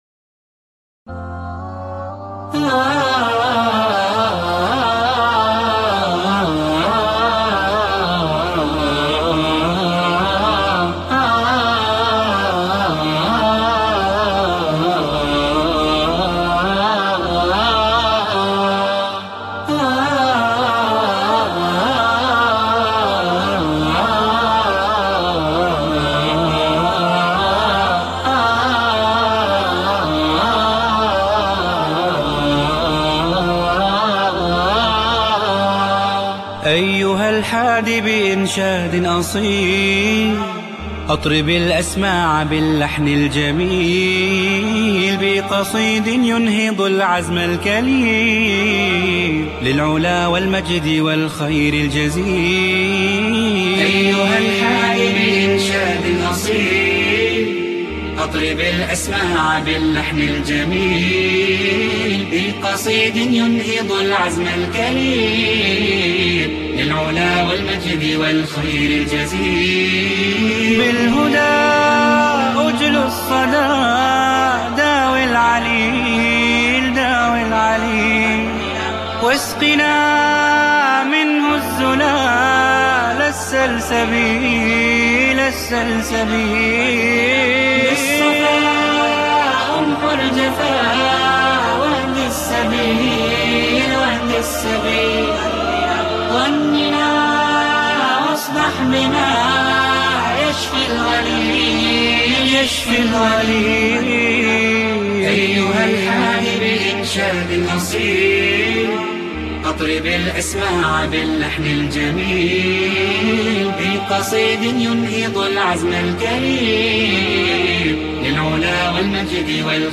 الاناشيد